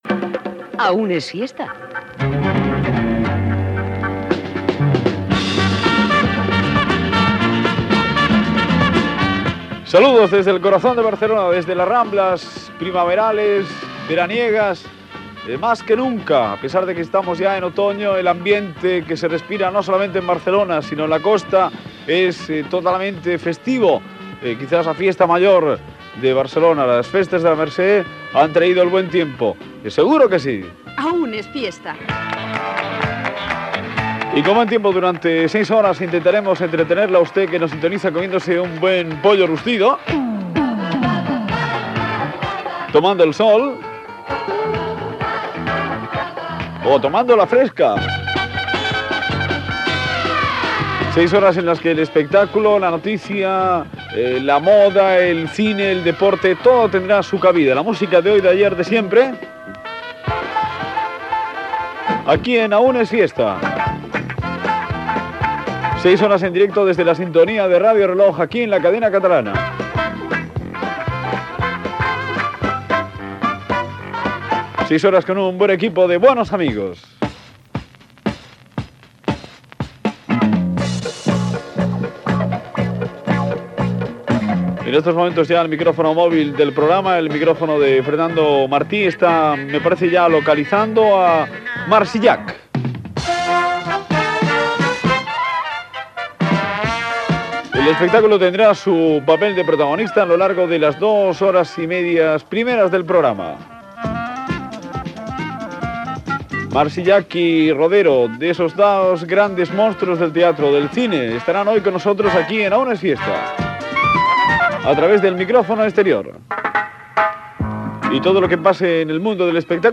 Careta del programa. Salutació, identificació, sumari del programa amb les seccions i els col·laboradors i indicatiu del programa.
Entreteniment
Magazín dels diumenges a la tarda i el capvespre.